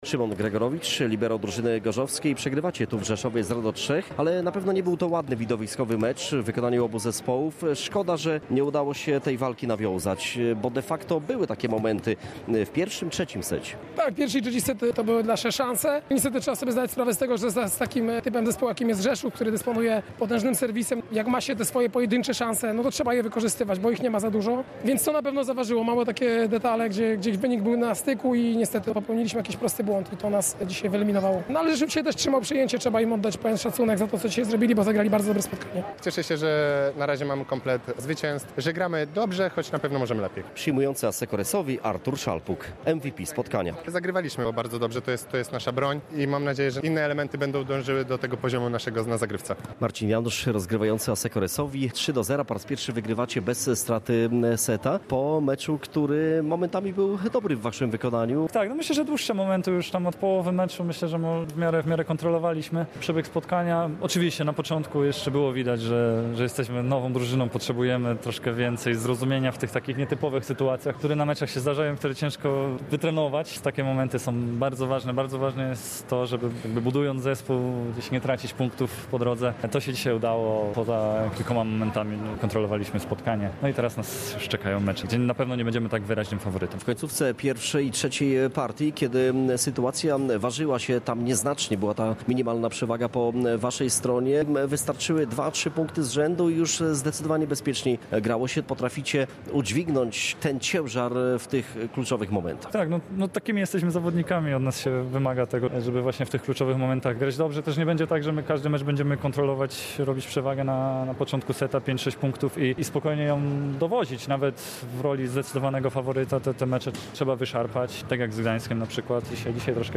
Pomeczowe opinie